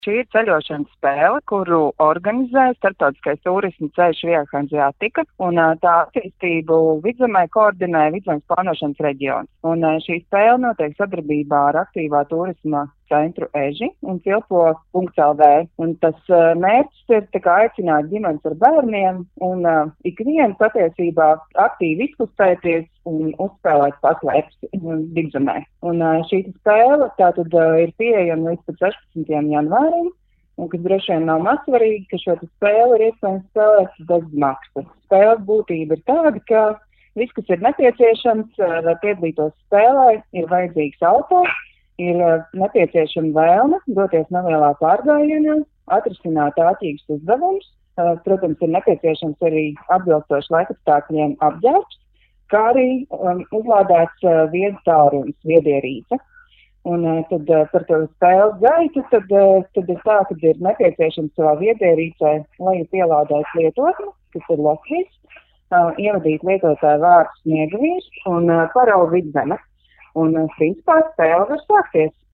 RADIO SKONTO Ziņās par ceļošanas spēli “Sniegavīru paslēpes Vidzemē”